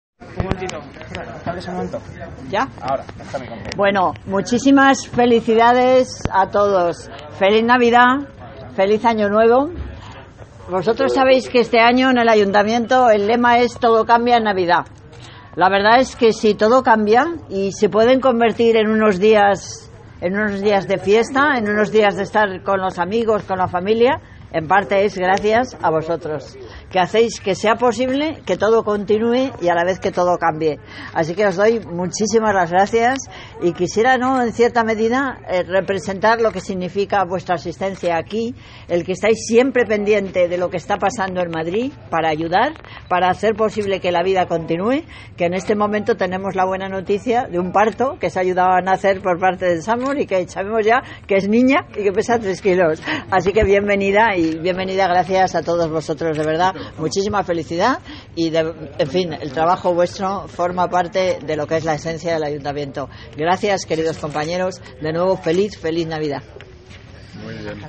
Nueva ventana:Mensaje alcaldesa de Madrid, Manuela Carmena, en su visita a los servicios de emergencia de guardia en Nochebuena